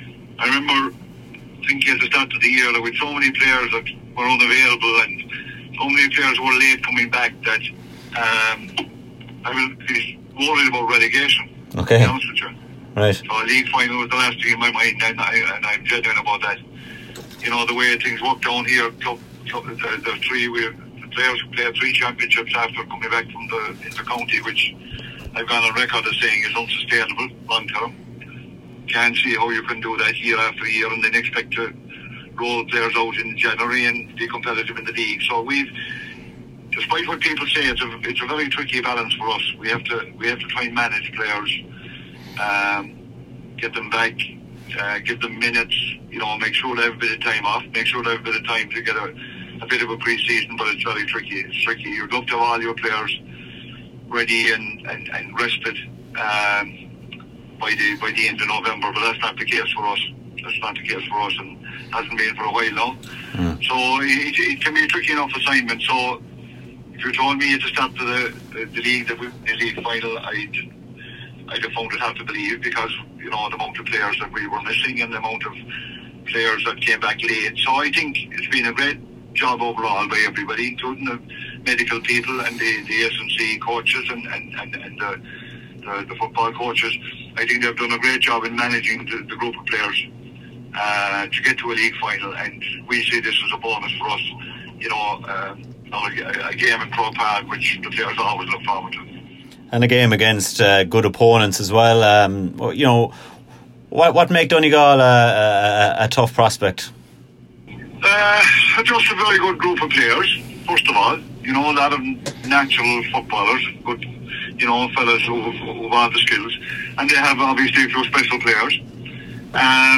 Kerry Manager Jack O’Connor